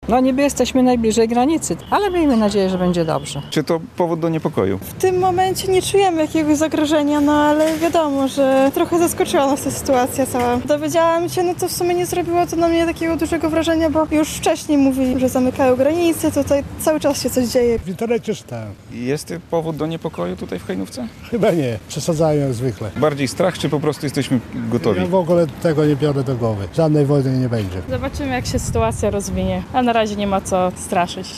Mieszkańcy Hajnówki o rosyjskich dronach